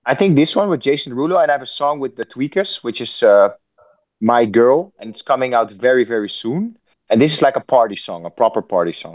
Tom smo prilikom intervjuirali R3HAB-a koji je oduševljen suradnjom s Jasonom Derulom.